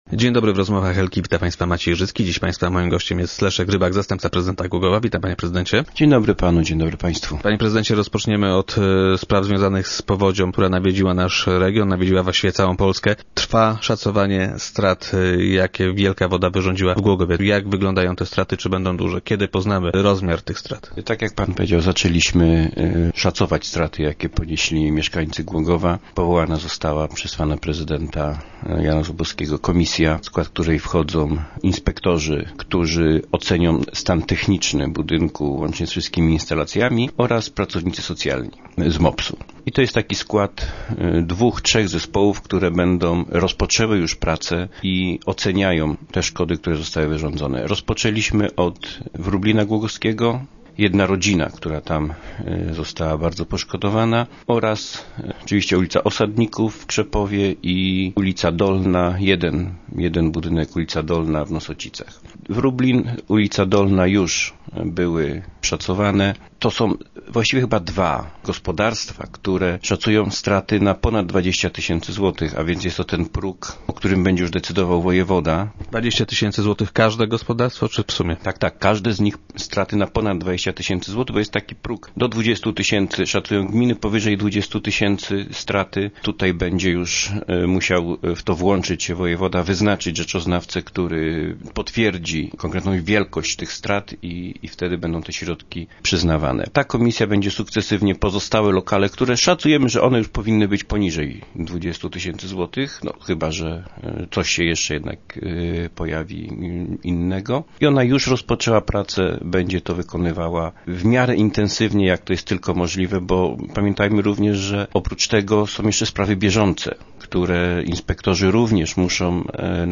Od ponad tygodnia pracuje specjalna komisja powołana przez prezydenta Głogowa. Przewodniczy jej wiceprezydent Leszek Rybak, który był dziś gościem Rozmów Elki.